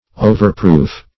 Overproof \O"ver*proof"\, a.